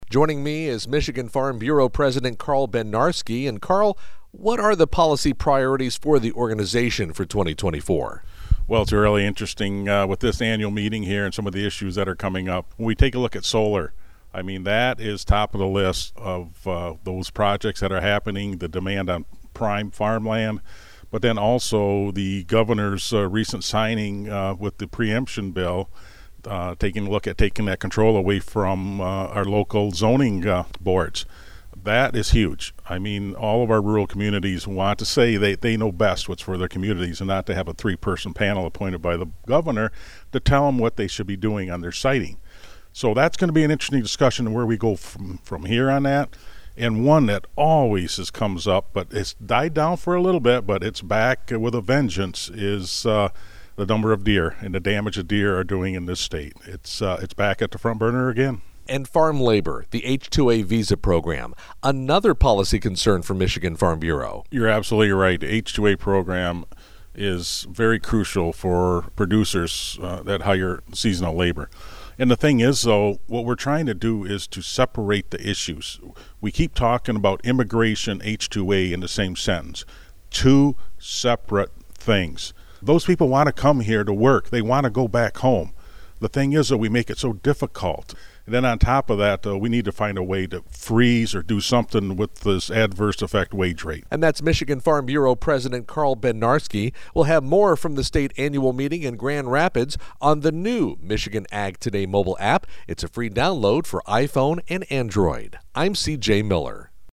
radio news report